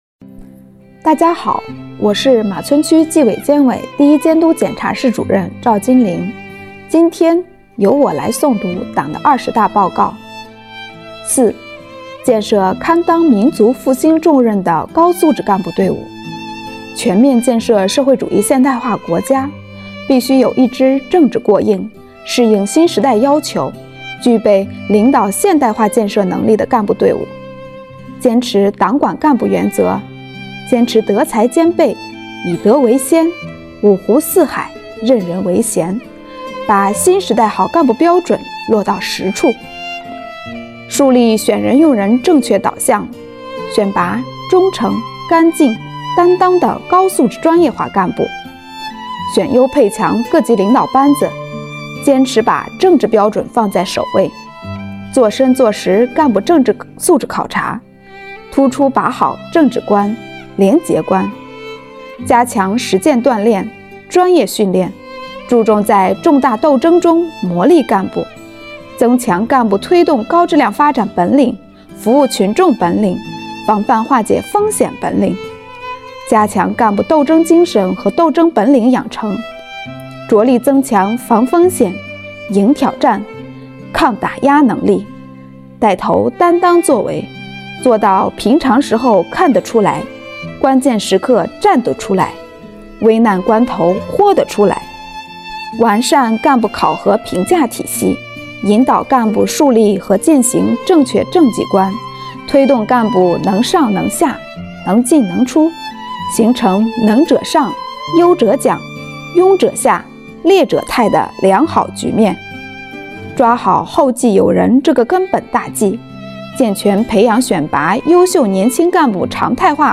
本期诵读人